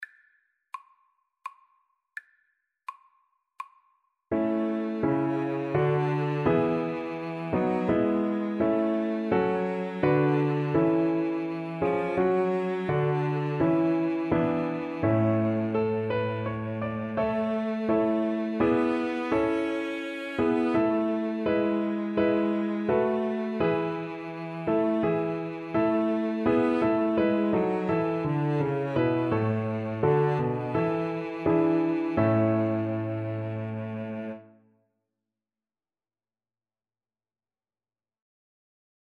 3/4 (View more 3/4 Music)
Piano Trio  (View more Easy Piano Trio Music)